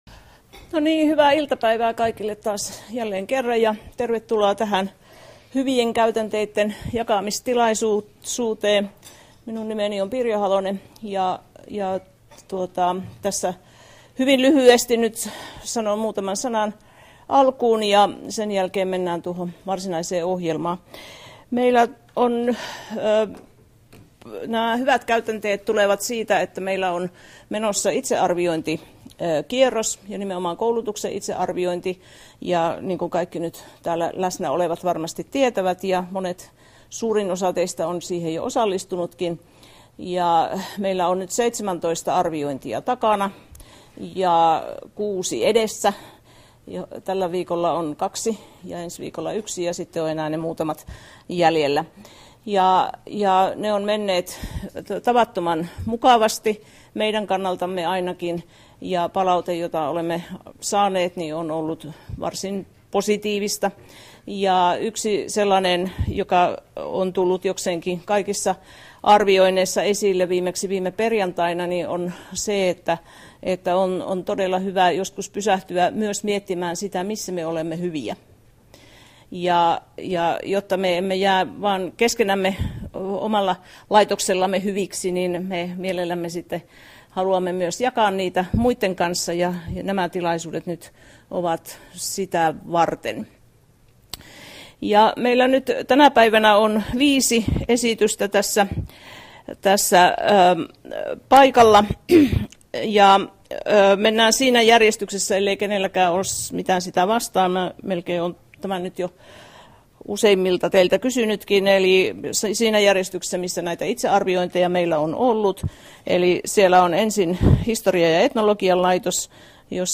Koulutuksen itsearvioinnin satoa 3, koulutusta antavat yksiköt esittelevät hyviä käytänteitään koulutuksessa.